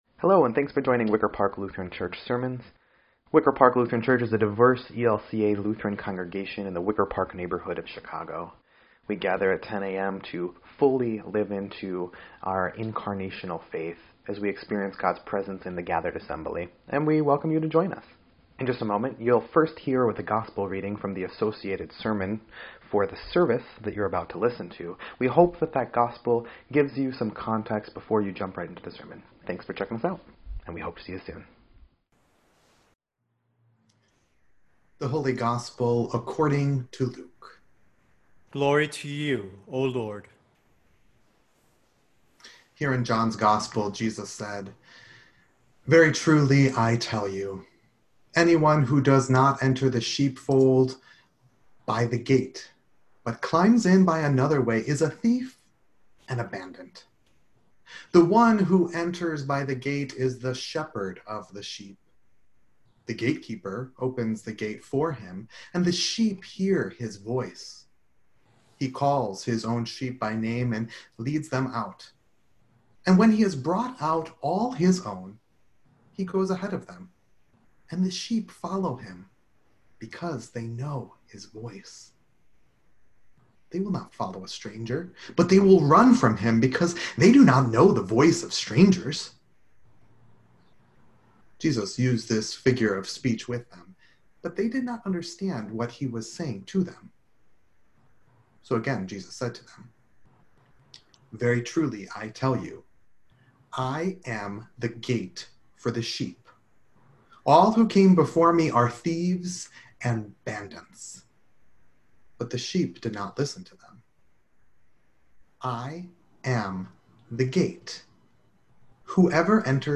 5.3.10-Sermon_EDIT.mp3